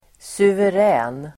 Uttal: [suver'ä:n]